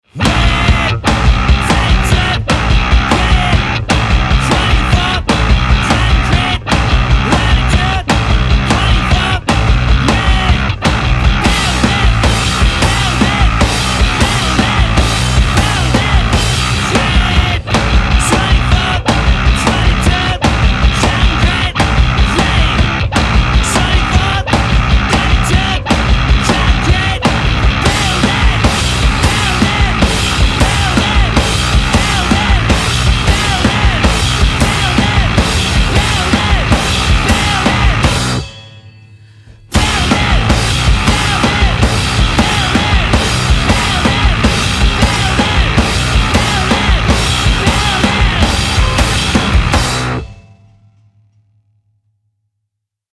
bass/vocals
drums